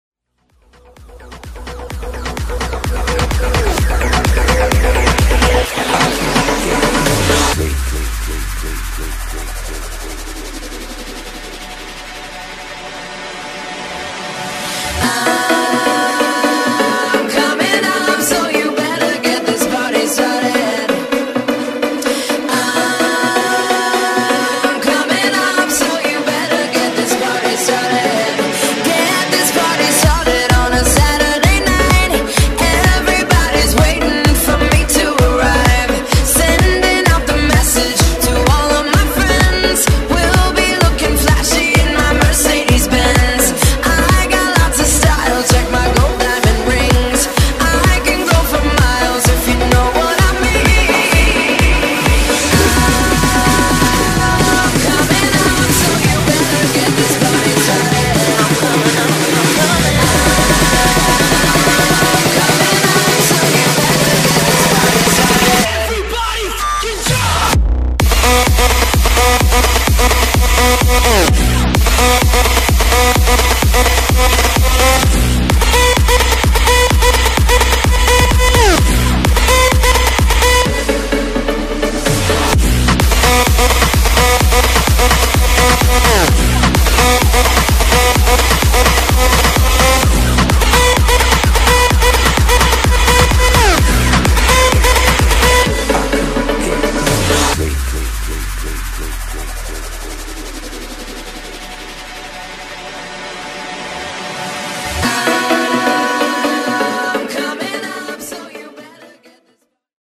Genres: EDM , MASHUPS , TOP40
Clean BPM: 128 Time